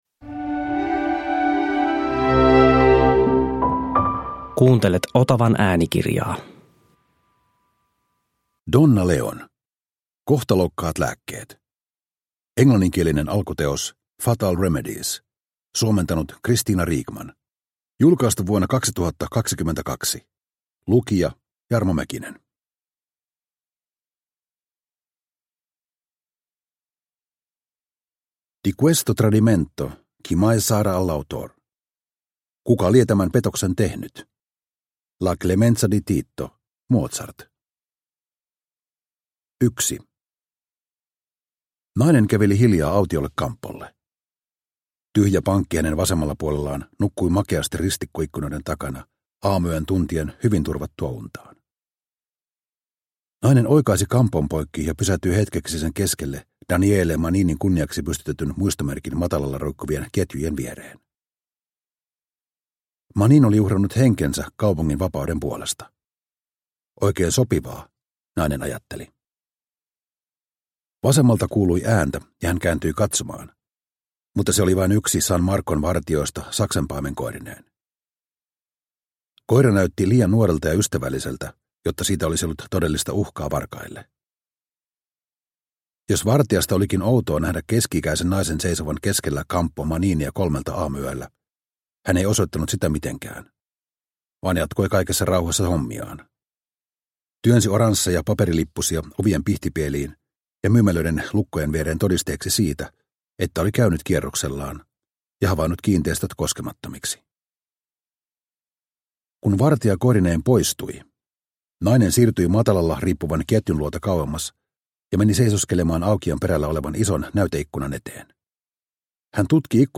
Kohtalokkaat lääkkeet – Ljudbok – Laddas ner